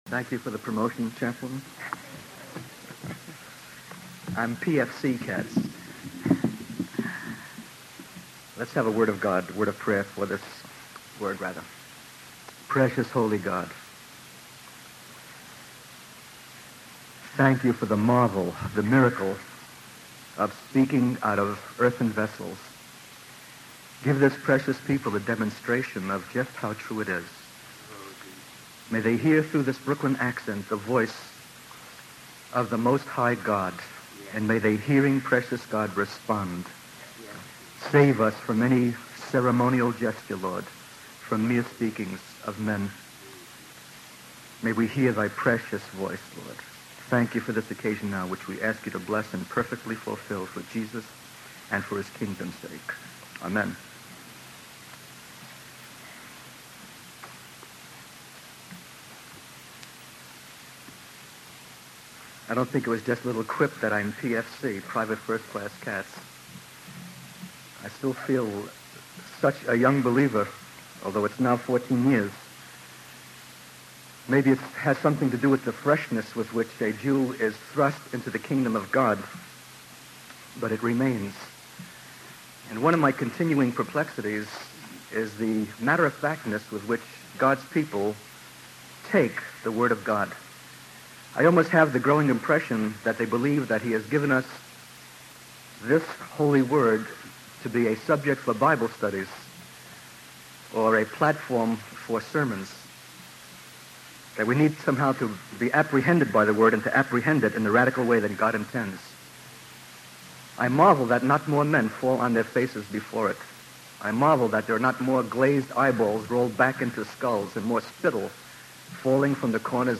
The sermon transcript discusses the importance of living according to God's word rather than being influenced by the world. It emphasizes the need for believers to choose between light and darkness, flesh and spirit, and the kingdom of God or the kingdom of this present world. The speaker highlights the power of the world in captivating people and causing them to focus on materialistic desires such as money, food, and clothing.